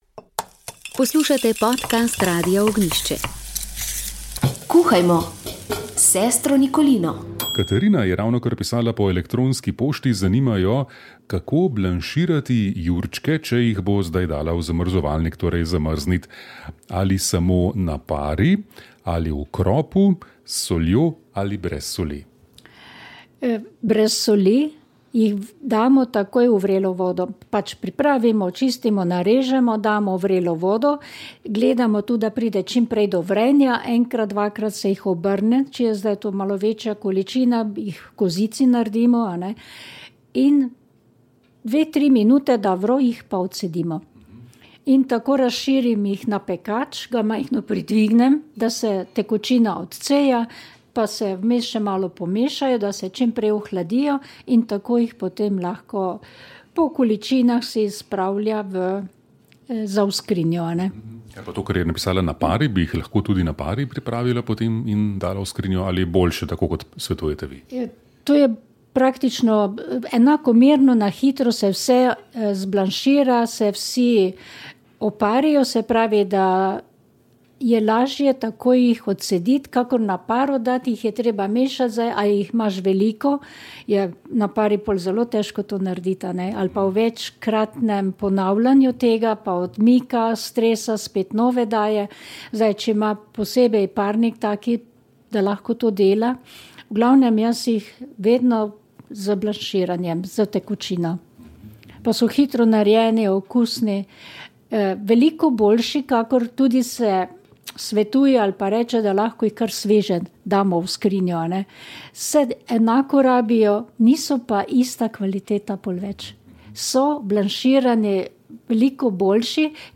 Parlamentarna jesen se je začela in politični oder je vse bolj poln novih in starih igralcev, ki iščejo podporo volivcev. Toda ljudje so naveličani praznih obljub in zahtevajo dejanja. Kakšne so strategije strank in kam vse to pelje, sta poskušala gosta odgovoriti v tokratni oddaji.